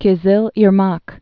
(kĭ-zĭl îr-mäk)